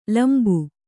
♪ lambu